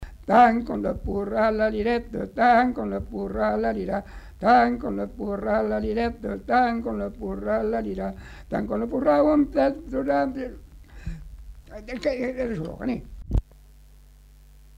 Aire culturelle : Savès
Genre : chant
Effectif : 1
Type de voix : voix d'homme
Production du son : chanté
Description de l'item : fragment ; refr.
Classification : danses